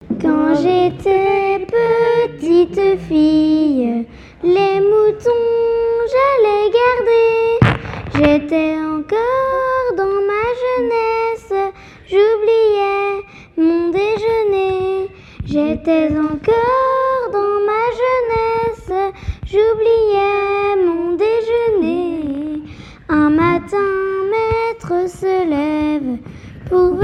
Genre strophique
chansons traditionnelles lors d'un concert associant personnes ressources et continuateurs
Pièce musicale inédite